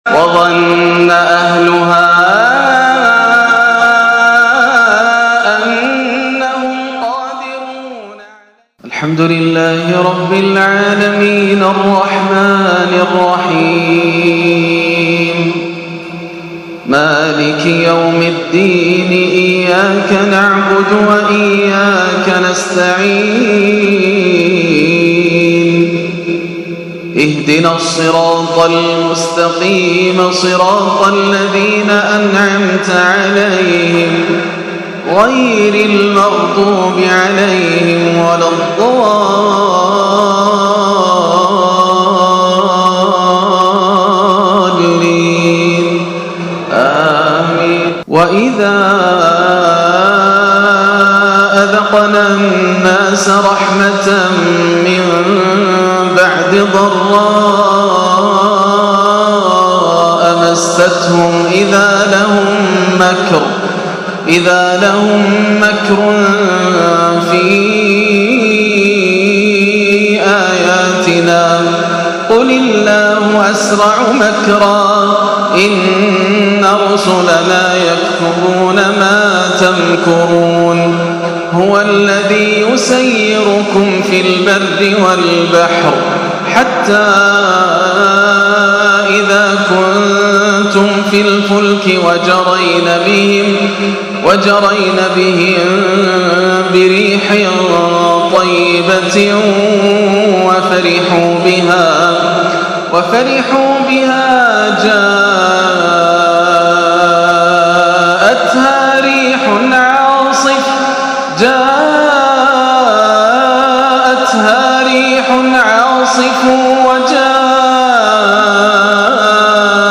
عشاء الثلاثاء 4-6-1439هـ من سورتي يونس 21-25 و الكهف 45-50 > عام 1439 > الفروض - تلاوات ياسر الدوسري